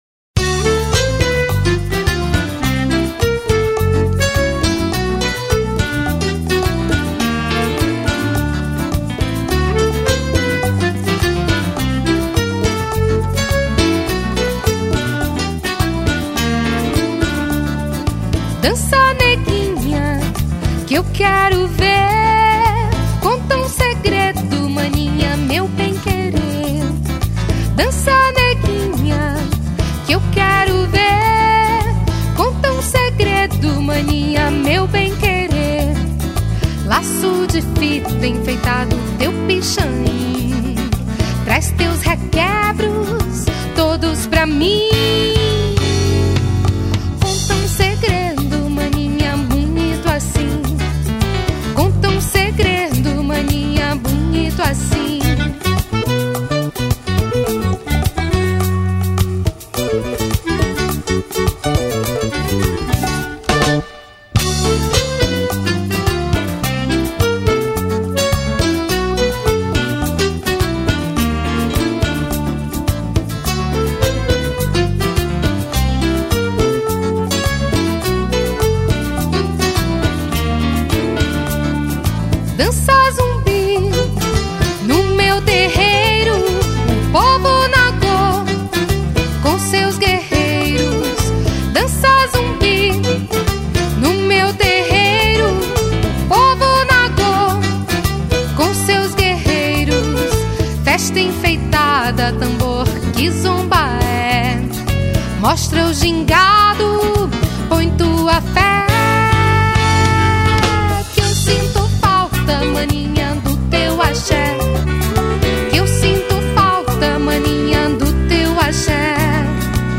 381   03:12:00   Faixa:     Afro-Axé
Voz
Baixo Elétrico 6, Guitarra
Clarinete
Teclados
Bateria
Percussão